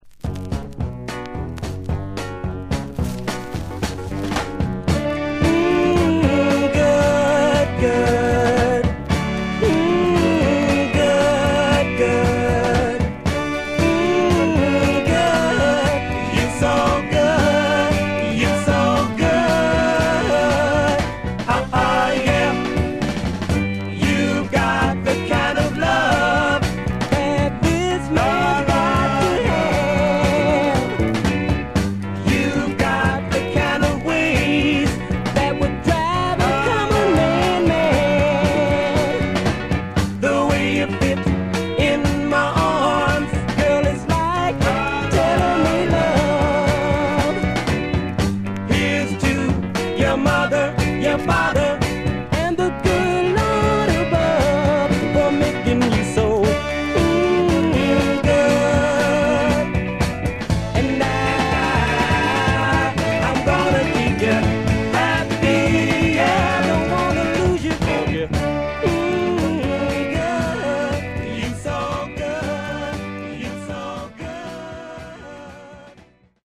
Mono
Soul